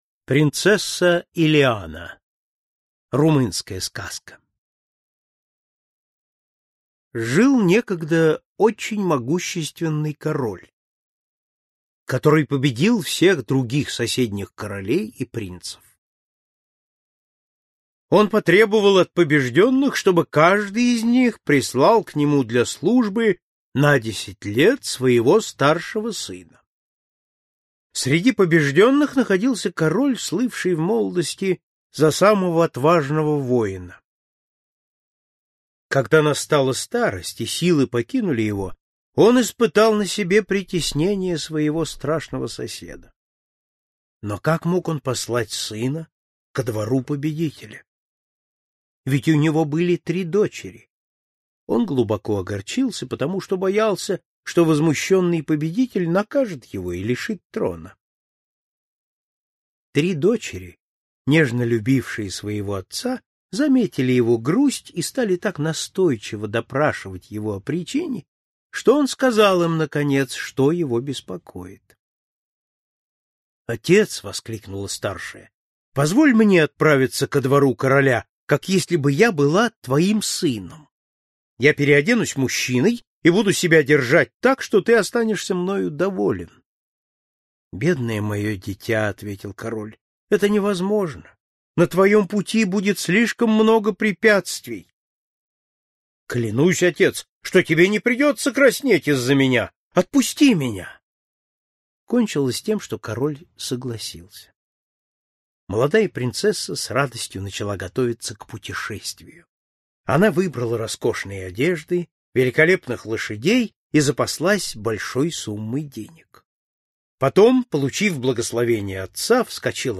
Аудиокнига Золотая книга сказок. Румынские сказки | Библиотека аудиокниг